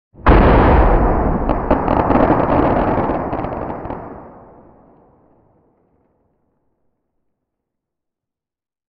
bloodclot-explode.ogg.mp3